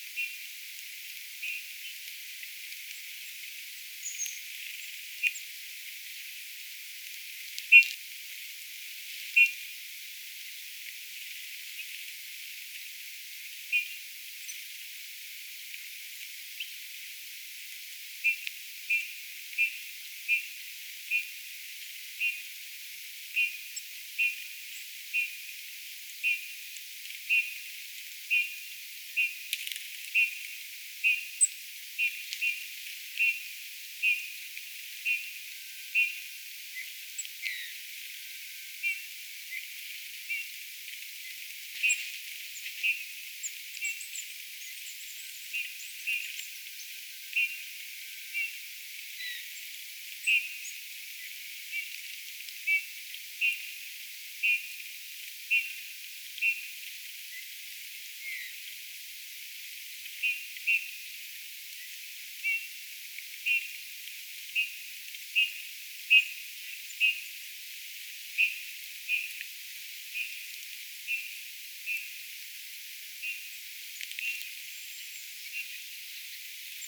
ehkä juuri kuvien punatulkkukoiras
ääntelee ison haapapuun latvassa
linturuokinnan vierellä
ehka_juuri_kuvien_punatulkkukoiras_aantelee_ison_haapapuun_latvassa_linturuokinnan_luona.mp3